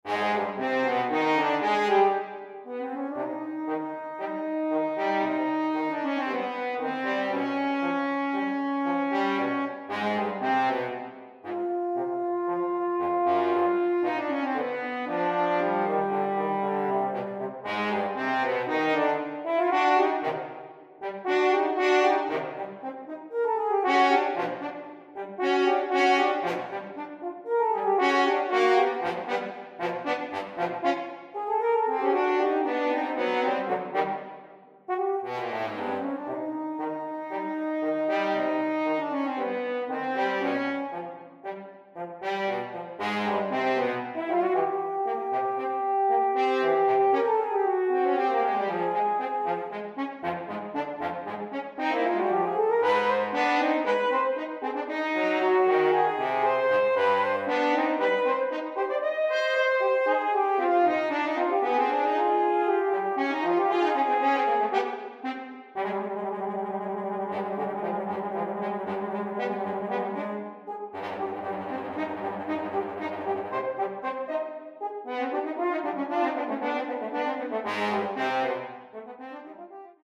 Voicing: French Horn Duet